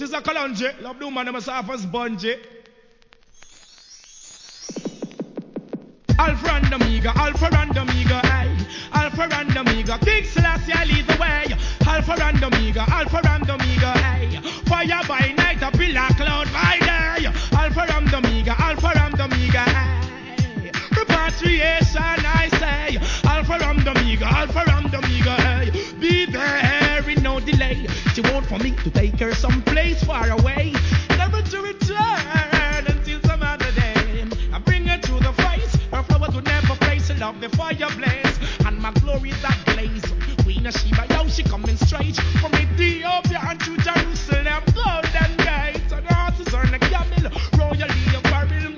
REGGAE
HIP HOP調のトラックで2001年リリース!!